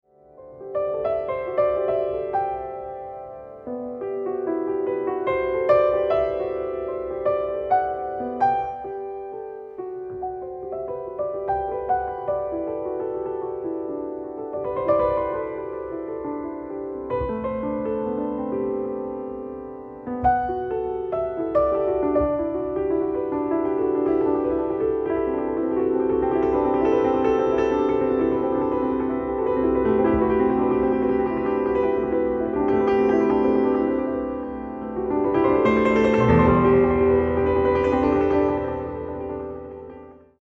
piano
clarinetto